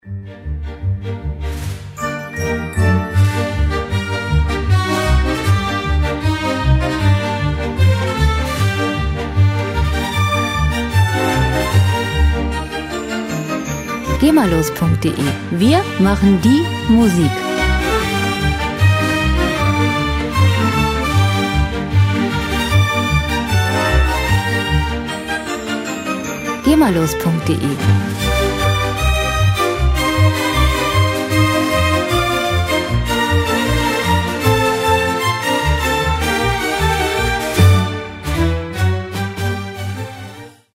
Musikstil: Klassische Musik
Tempo: 153 bpm
Tonart: G-Moll
Charakter: mitreißend, feurig
Instrumentierung: Orchester